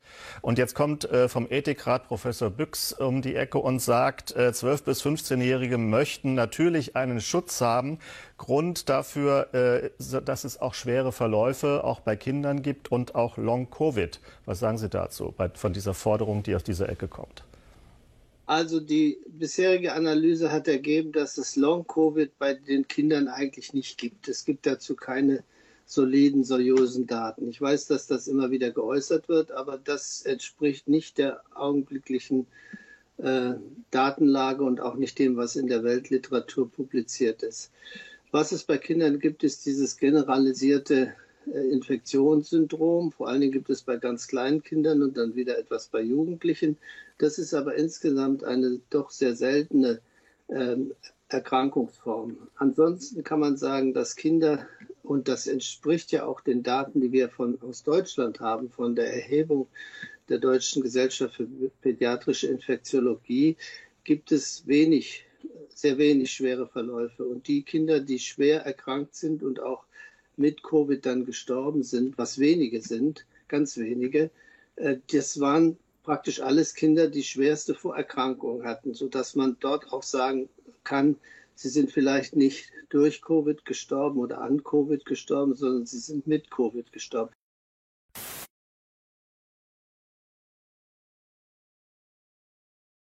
Wenn der Vorsitzende der Ständige Impfkommission (STIKO), Prof. Thomas Mertens, live bei Phoenix, mit ein paar wenigen Sätzen, die Impfpropaganda von Ethikrat und RKI für Kinder zerlegt, dann sind wir auf gutem Weg...